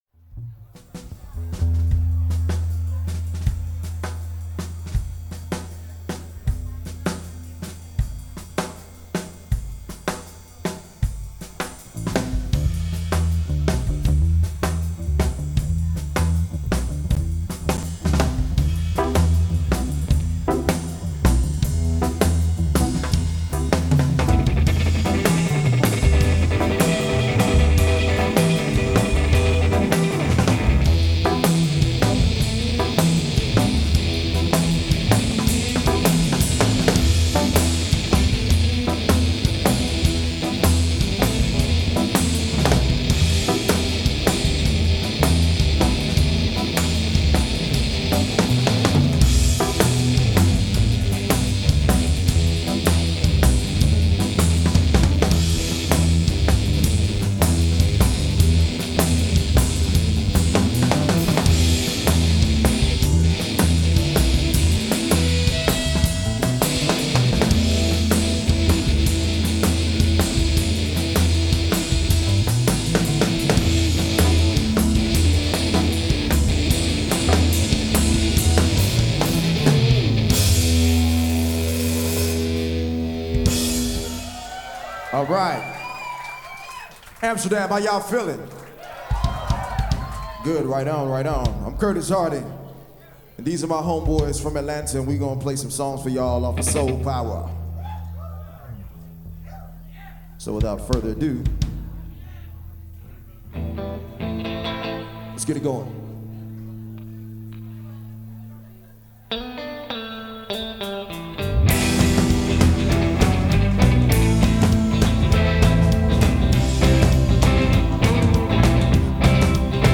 A taste of Soul
in a concert recorded live at Paradiso on November 23rd